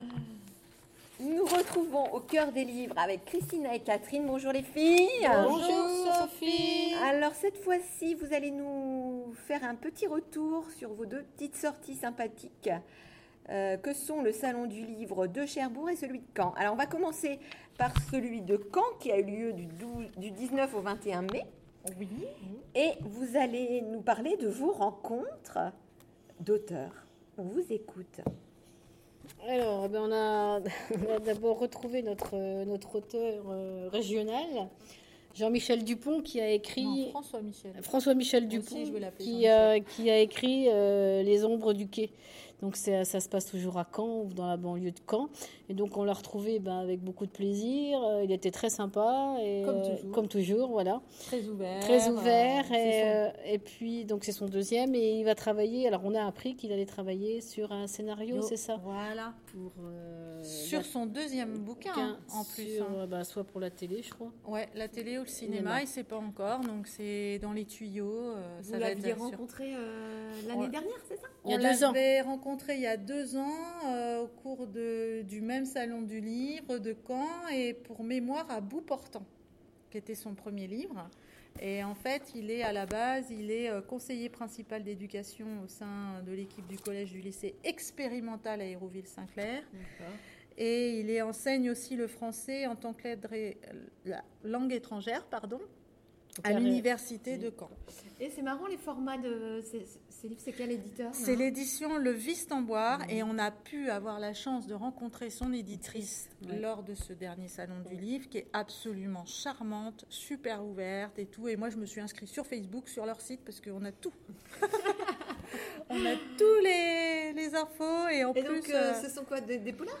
Au coeur des livres : rencontres aux salons du livre de Caen et de Cherbourg